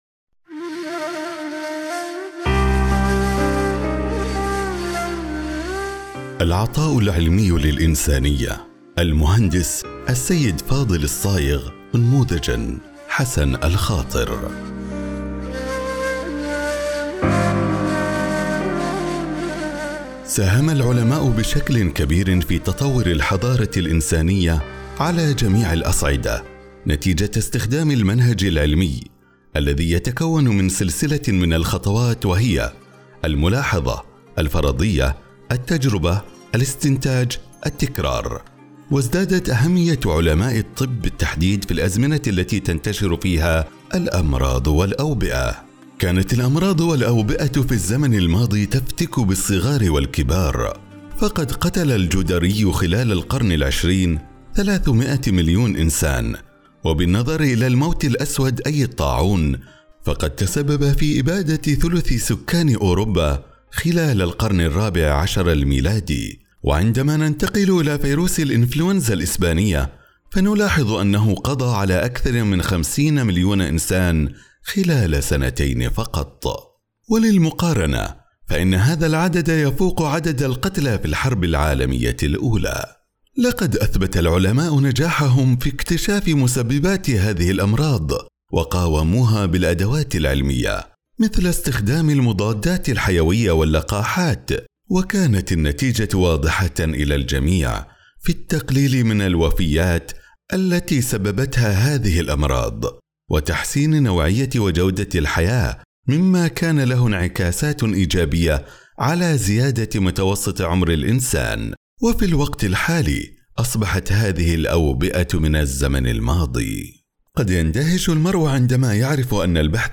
التعليق الصوتي